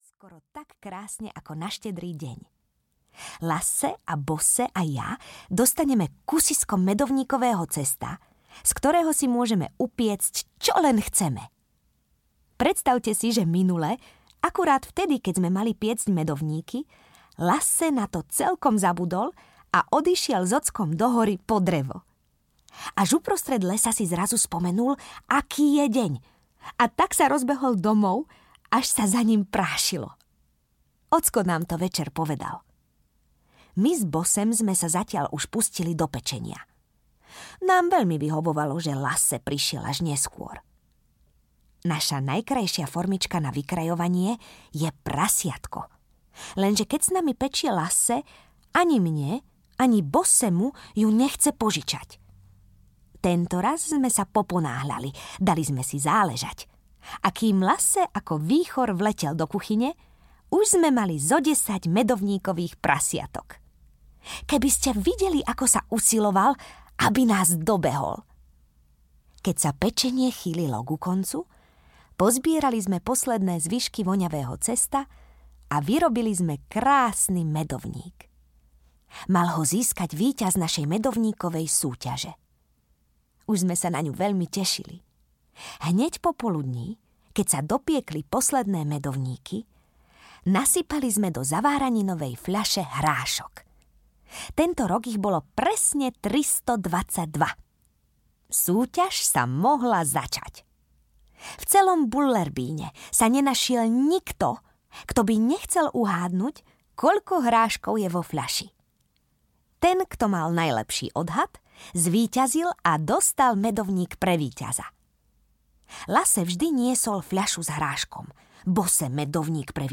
Oslavy v Bullerbyne audiokniha
Ukázka z knihy
• InterpretTáňa Pauhofová